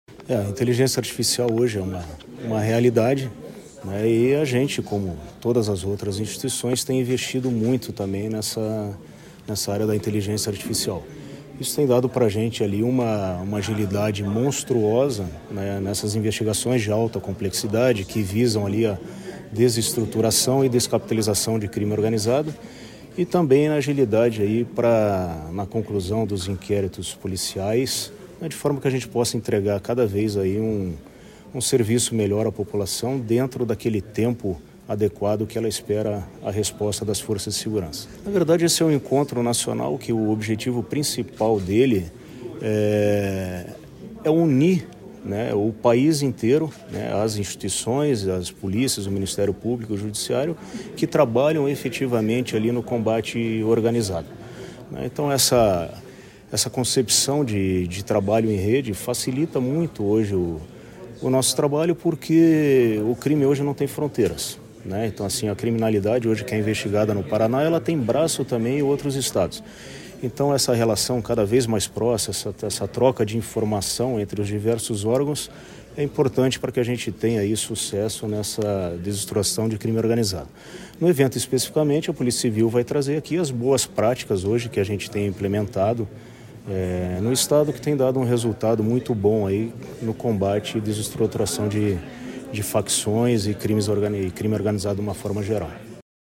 Sonora do delegado-geral da PCPR, Silvio Rockembach, sobre a realização do 3º Encontro Técnico da Renorcrim em Curitiba | Governo do Estado do Paraná